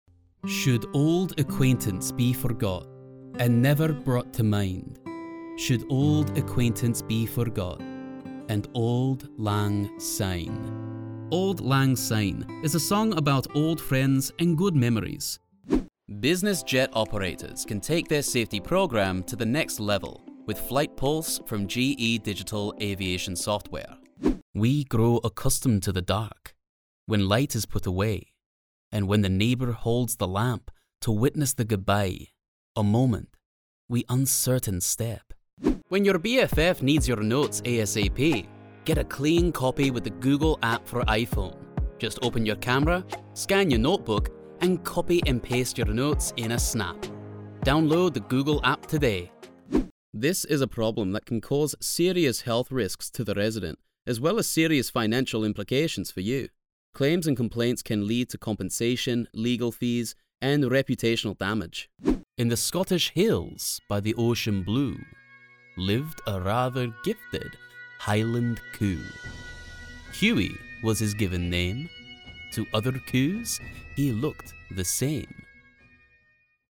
Explainer Videos
Scottish Warm Friendly Natural